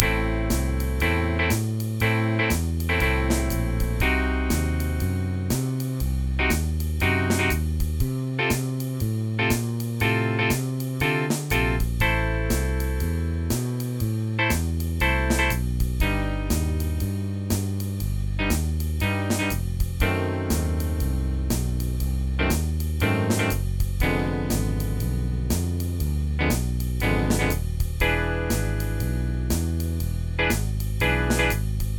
in the swing jazz style at 120 bpm